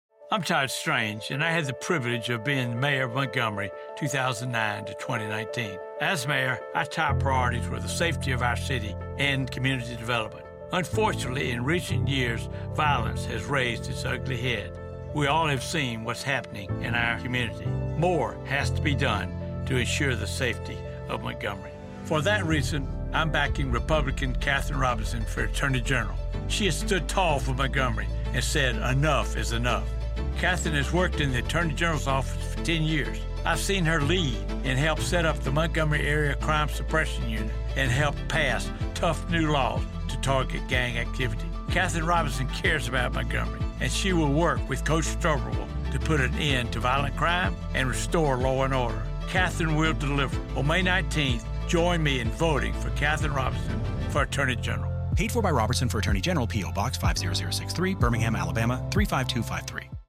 On Tuesday, Robertson’s campaign returned to the subject when former Montgomery Mayor Todd Strange endorsed her in a radio advertisement.
Todd-Strange-Radio-Ad-For-Katherine-Robertson.mp3